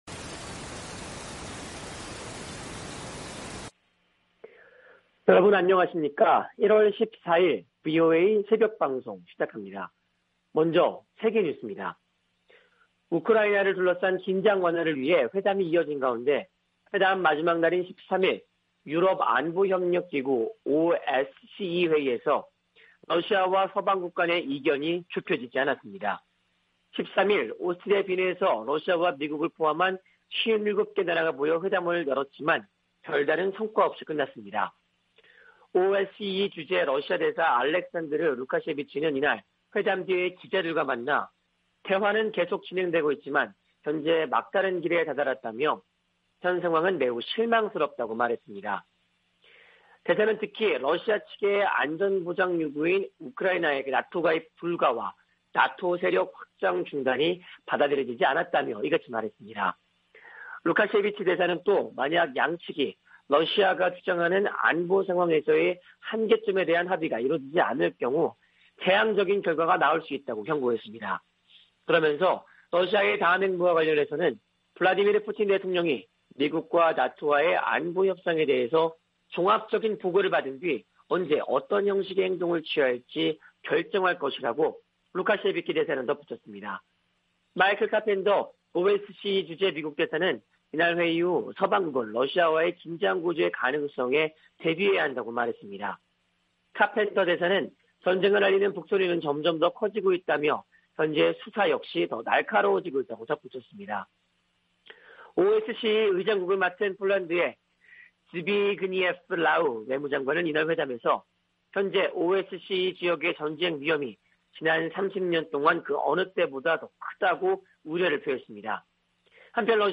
세계 뉴스와 함께 미국의 모든 것을 소개하는 '생방송 여기는 워싱턴입니다', 2021년 1월 14일 아침 방송입니다. '지구촌 오늘'에서는 유럽안보협력기구가 우크라이나 관련 현안을 논의한 소식, '아메리카 나우'에서는 투표권 확대 법안 상원 처리에 난항을 겪는 이야기 전해드립니다.